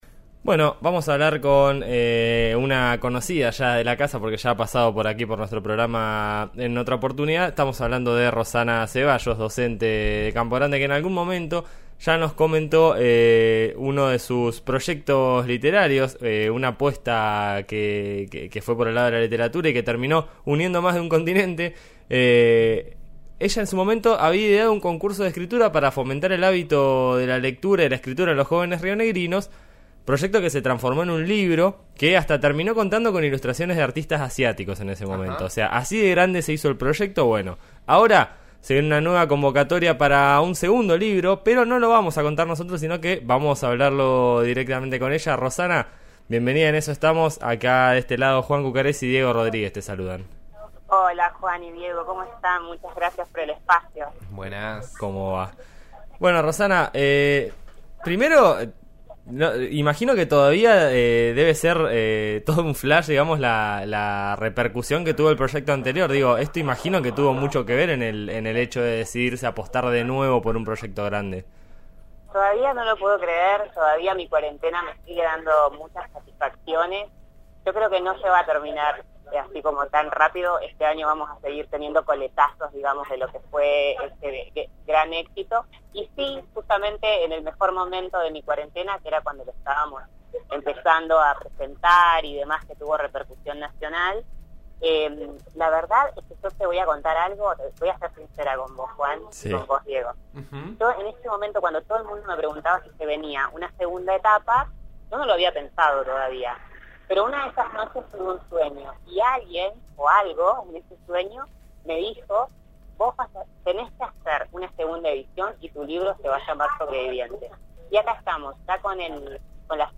En este caso, la convocatoria de escritos abarca a los estudiantes de todas las secundarias de la ciudad. La nota al aire de En Eso Estamos, por RN RADIO (FM 89.3 en Neuquén).